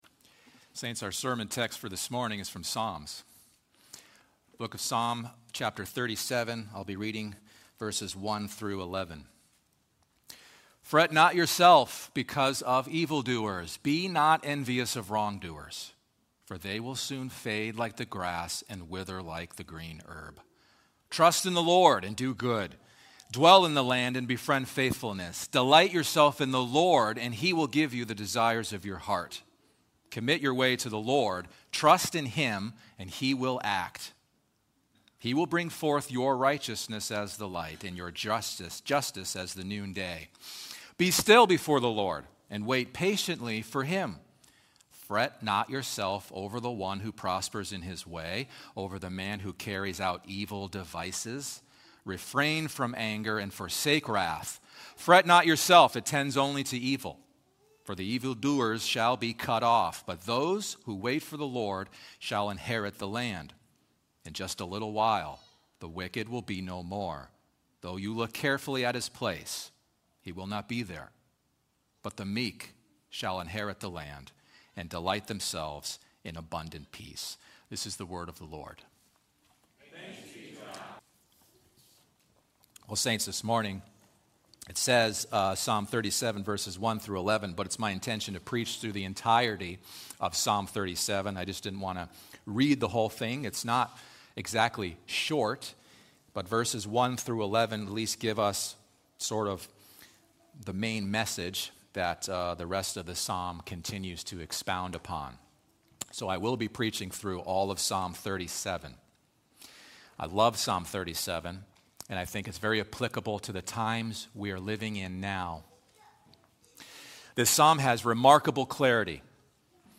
preaches on how the Christian must not fret about things he has no control over, but must instead trust in God and His provision.